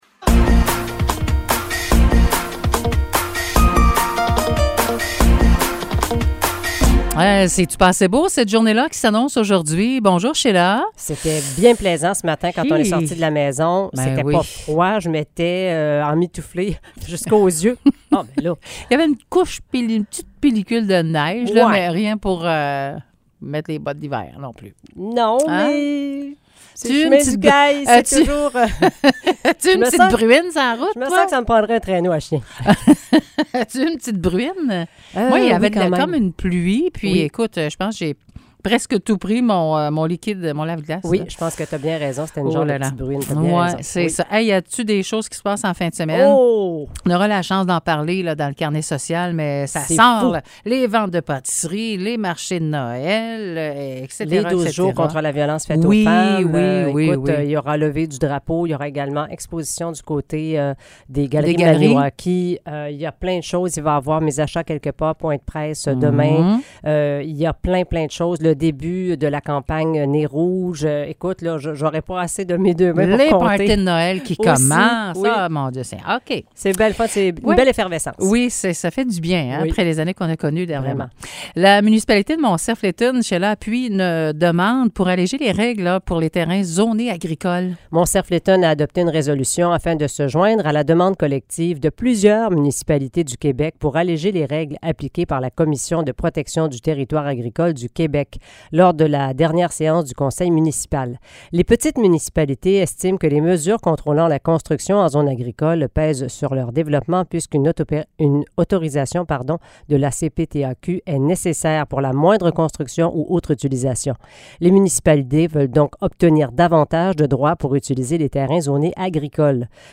Nouvelles locales - 23 novembre 2022 - 9 h